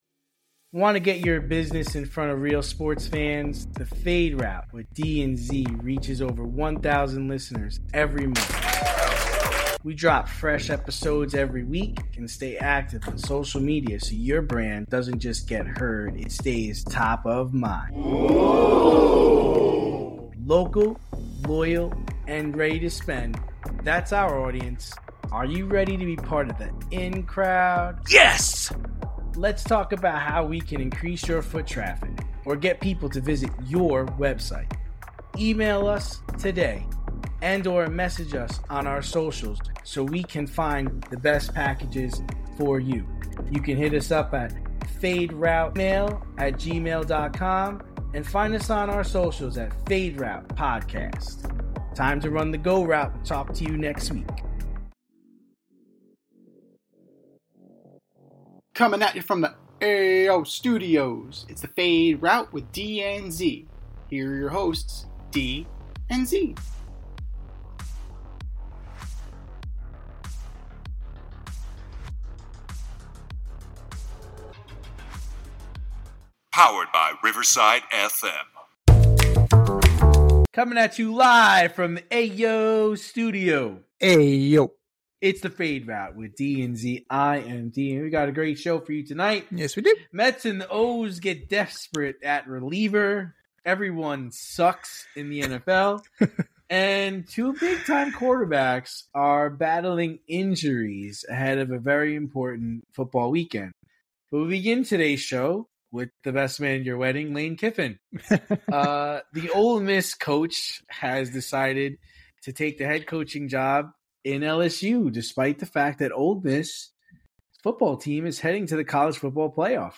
two veteran sports aficionados and lifelong friends, as they dissect the week’s top stories with wit and a touch of New York flair.